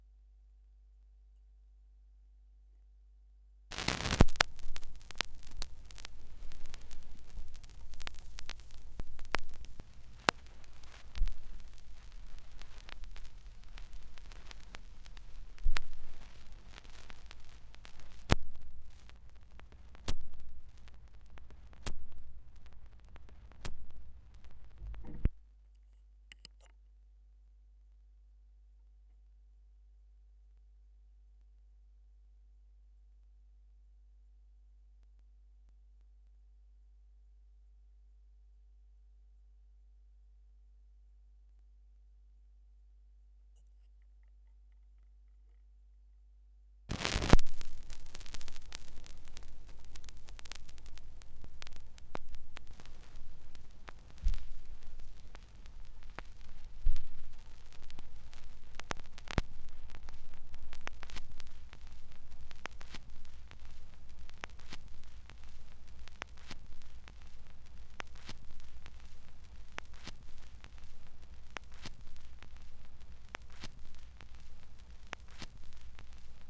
2017 Schallplattengeräusche (3).m3u